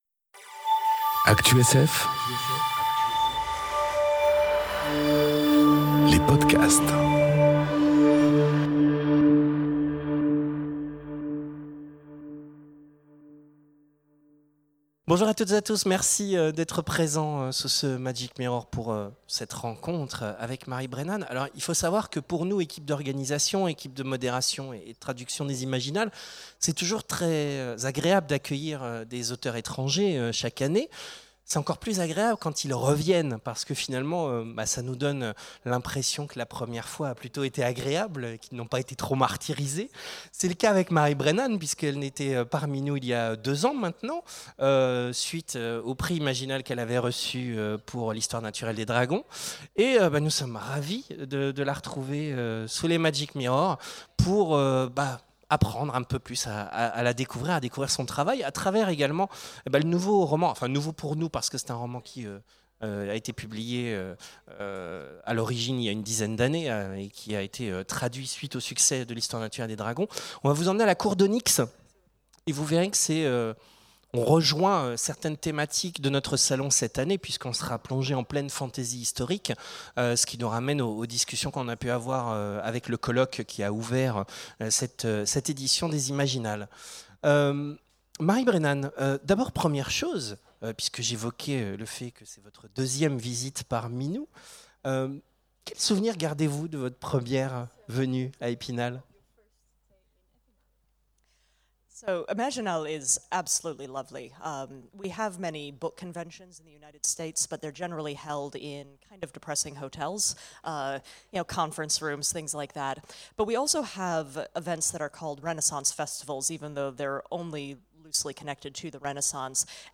Entretien avec Marie Brennan enregistré aux Imaginales 2018